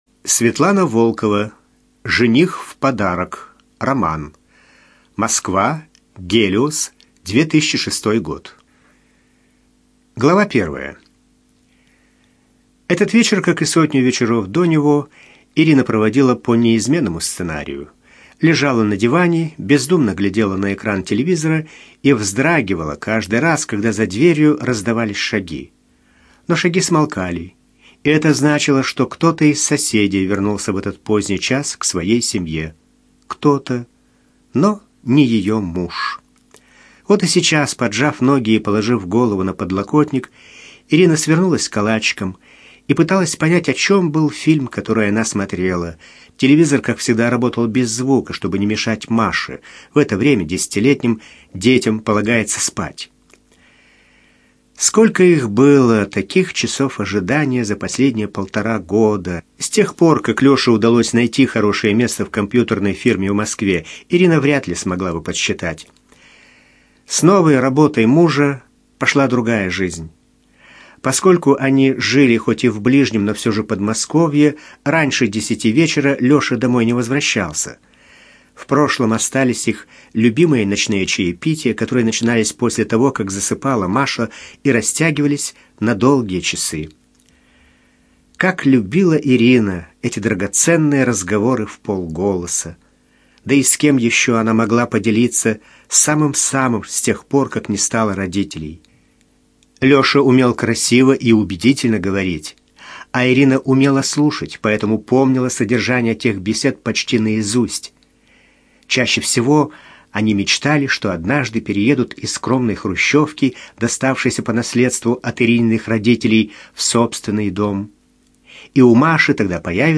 ЖанрЛюбовная проза
Студия звукозаписиЛогосвос